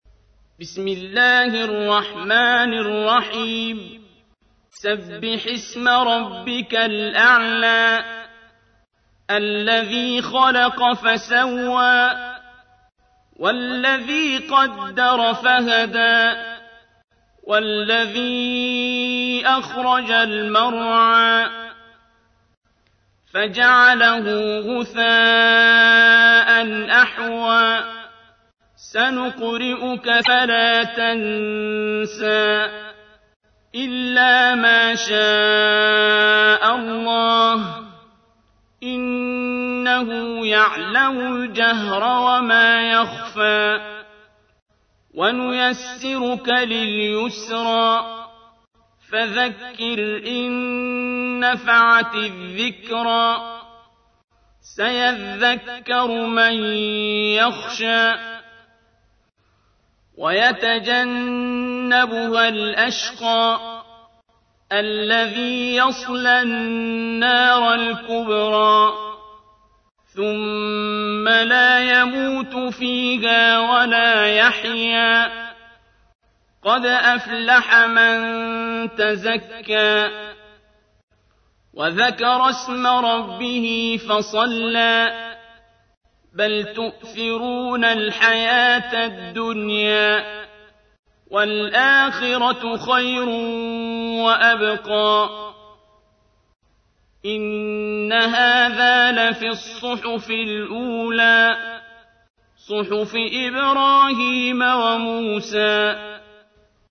تحميل : 87. سورة الأعلى / القارئ عبد الباسط عبد الصمد / القرآن الكريم / موقع يا حسين